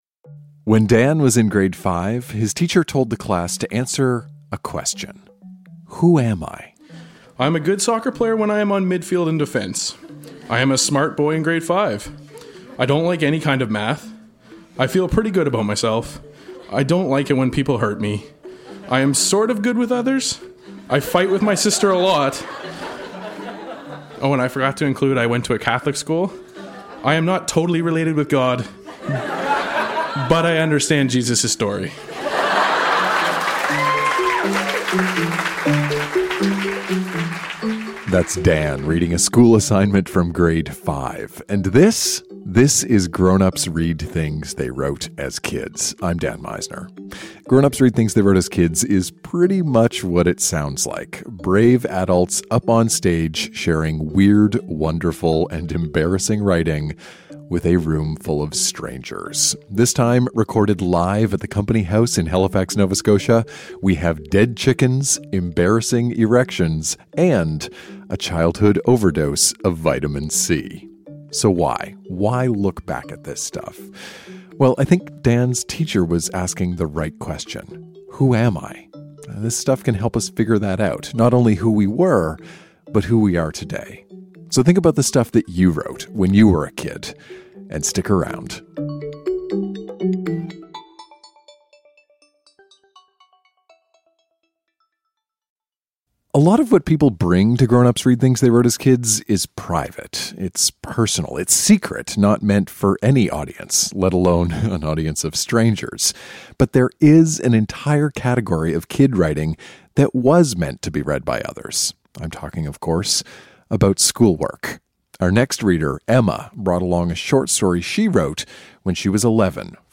Dead chickens, embarrassing erections, and a childhood overdose of vitamin C. Recorded live at The Company House in Halifax, Nova Scotia.